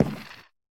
creaking_heart_step3.ogg